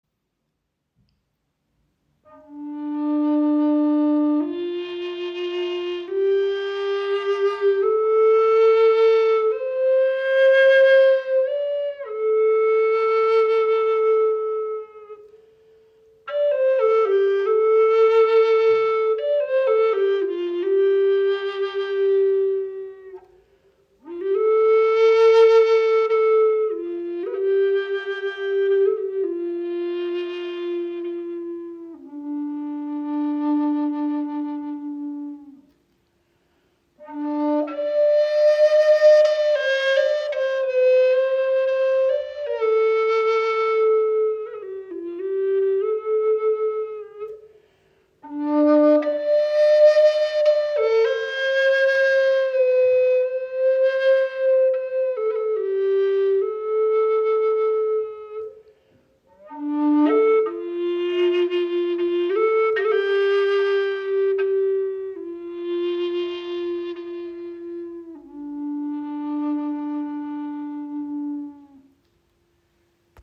Die D-Moll-Stimmung berührt tief, wirkt erdend und öffnet einen stillen Raum für innere Einkehr und heilende Klangarbeit.
Diese handgefertigte Bassflöte in D-Moll vereint einen tiefen, warmen Klang mit einer kompakteren Bauweise und ist dank der schmaleren Ein-Zoll-Bohrung besonders gut für kleinere Hände geeignet. Der Ton in D-Moll wirkt zugleich beruhigend und zentrierend – eine kraftvolle Klangfarbe für spirituelle Praxis, Klangreisen und therapeutische Arbeit.
So entsteht ein edles, samtig dunkles Finish und eine brillante Klangqualität, die klar, definiert und tief in ihrer Wirkung ist.
High Spirits Flöten sind Native American Style Flutes.